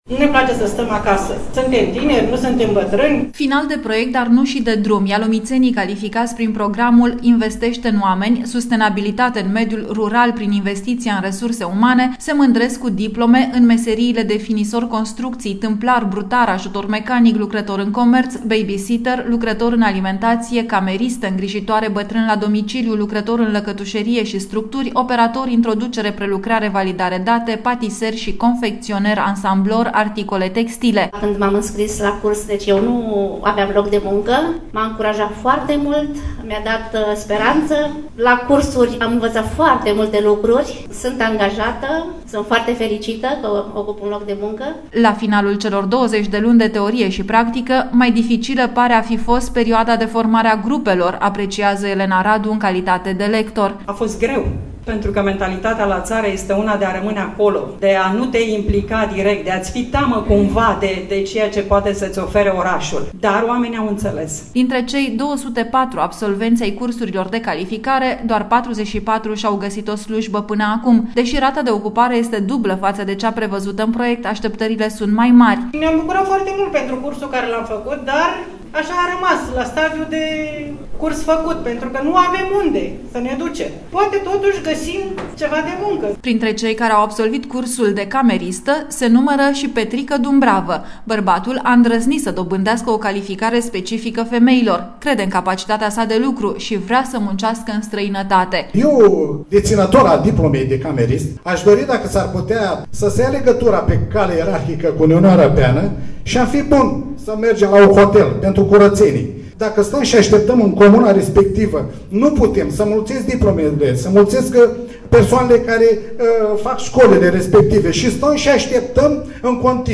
reportaj-ialomiteni-calificati.mp3